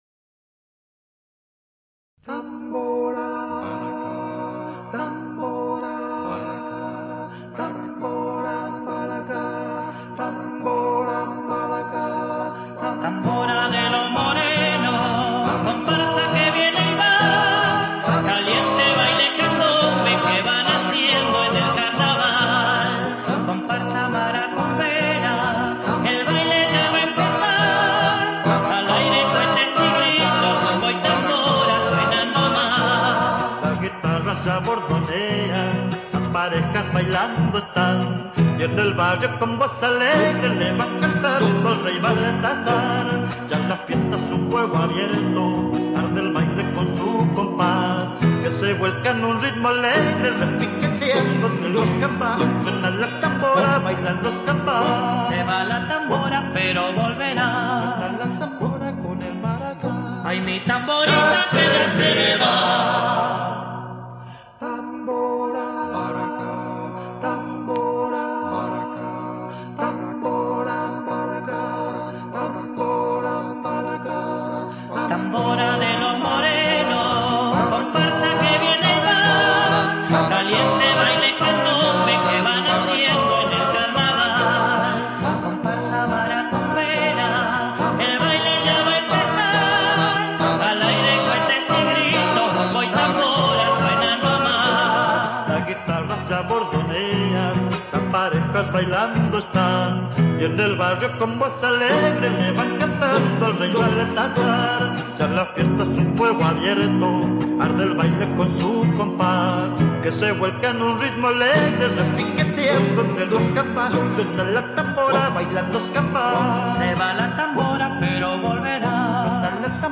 Polca Candombe